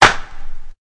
Clap (1).wav